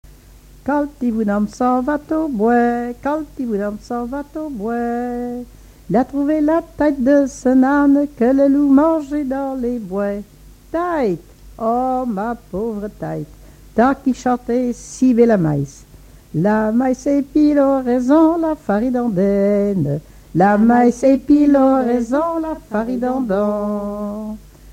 Mémoires et Patrimoines vivants - RaddO est une base de données d'archives iconographiques et sonores.
Catégorie Pièce musicale inédite